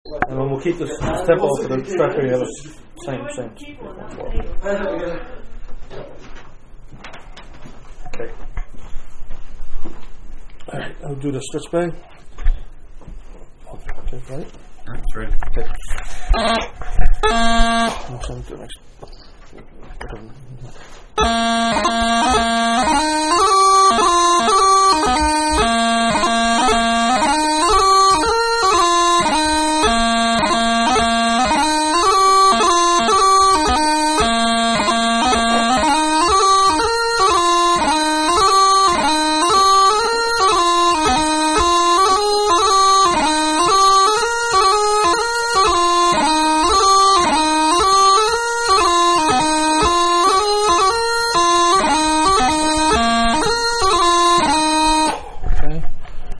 Strathspey 112 bpm